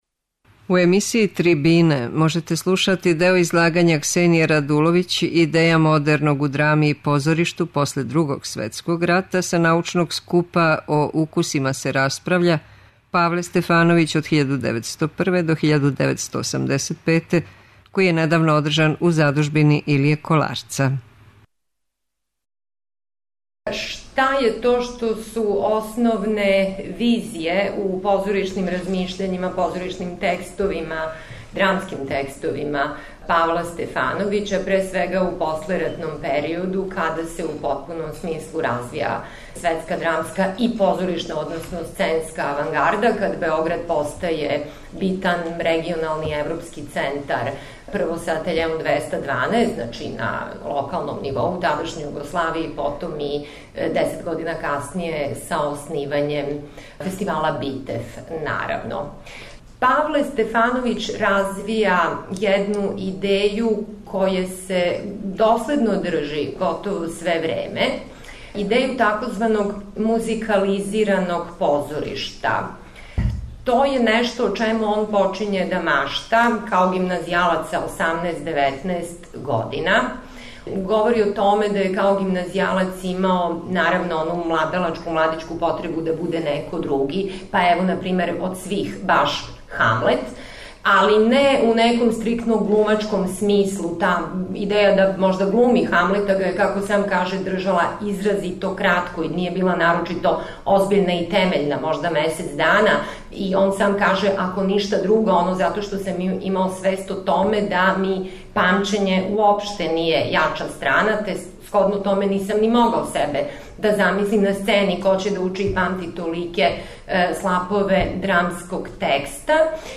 Трибине